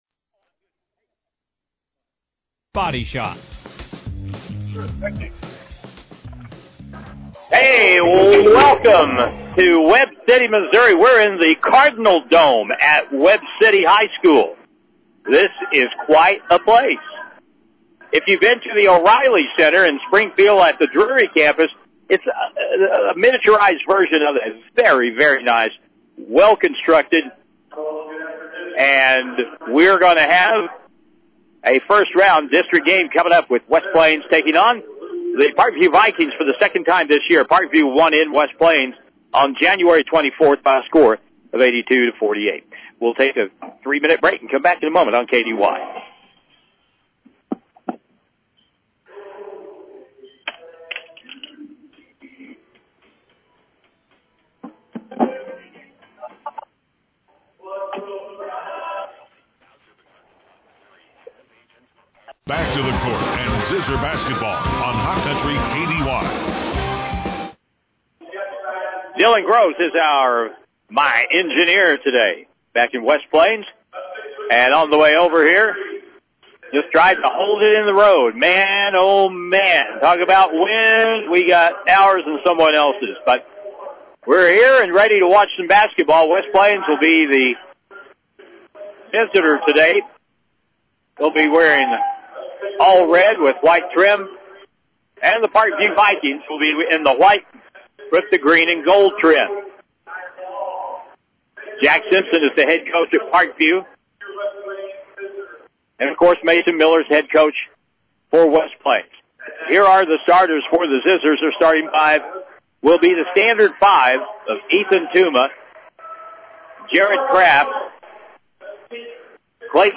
West-Plains-Zizzer-Boys-Basketball-vs.-Parkview-Vikings-DISTRICT-GAME-3-5-25.mp3